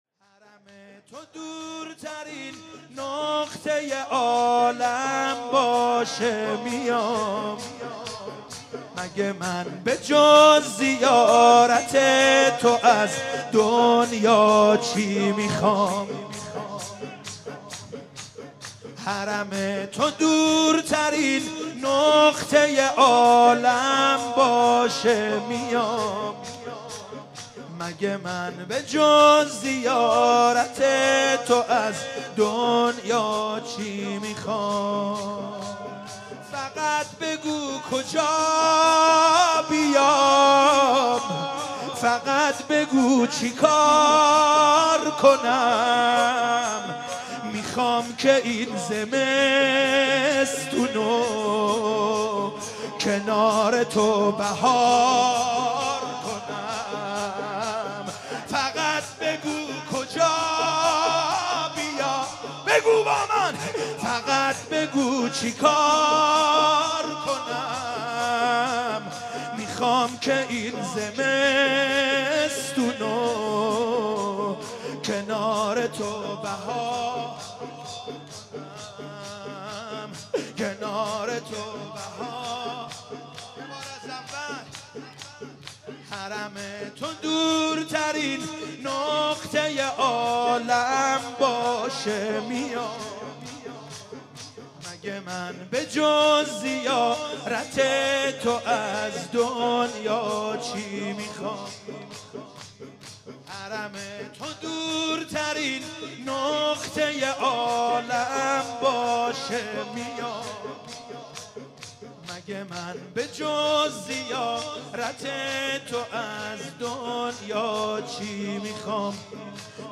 شب 1 فاطمیه 95 - هیئت یازهرا سلام الله علیها قم - شور - حرم تو دورترین نقطه ی